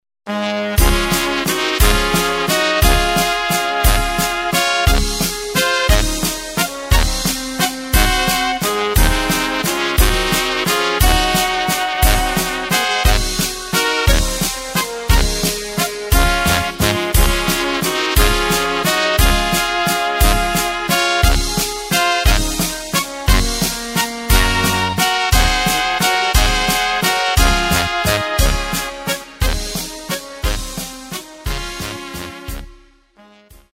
Takt:          3/4
Tempo:         176.00
Tonart:            Db
Walzer Blasmusik aus dem Jahr 1992!